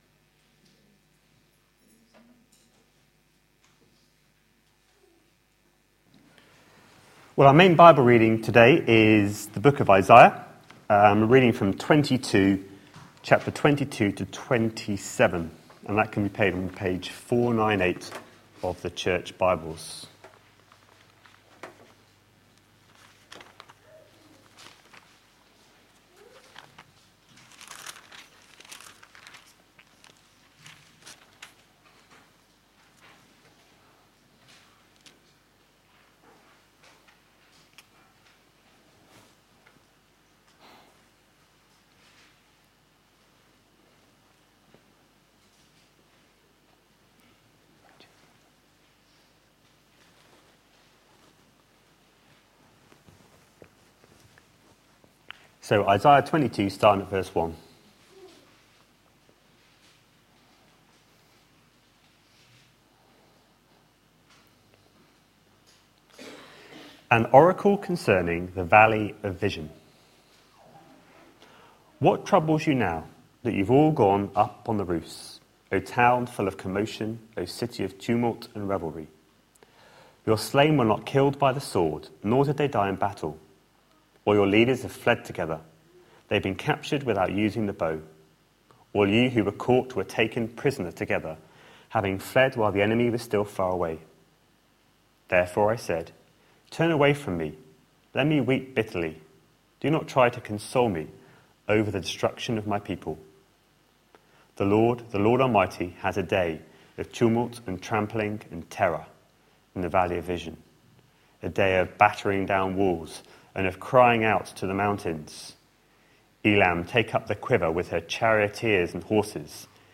A sermon preached on 26th April, 2015, as part of our No one compares series.